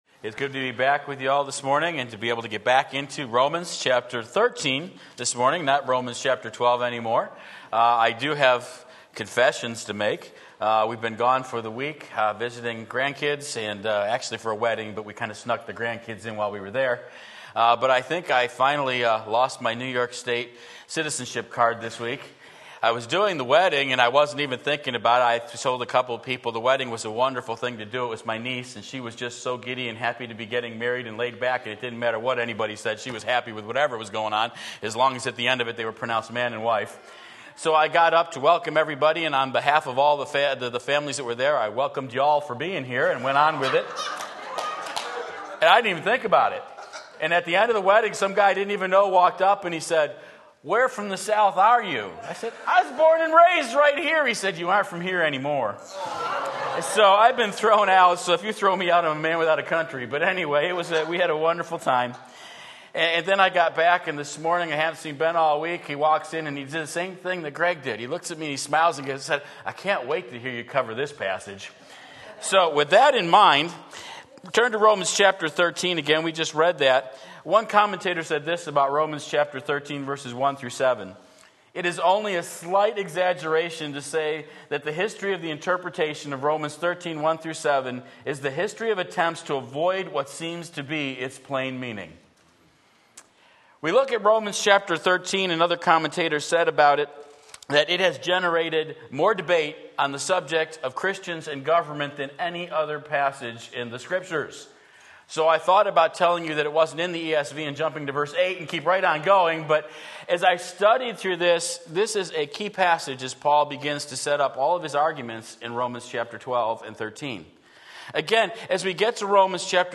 Sermon Link
Submitting to Government Romans 13:1-7 Sunday Morning Service